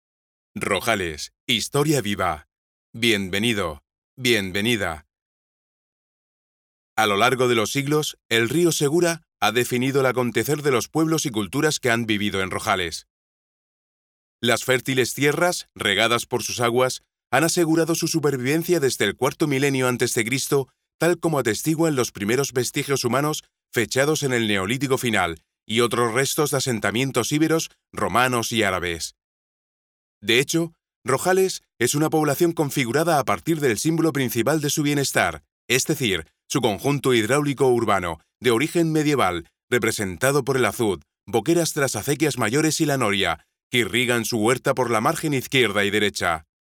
Voice actor for television, radio, film commercial recordings. Dramatic interpretation. Company videos, etc
Sprechprobe: eLearning (Muttersprache):
Voice actor who can be kind, energetic, arrogant, corporate, compassionate, rebellious, caring, evil, gentle, persuasive ......
audioguia demo rojales.mp3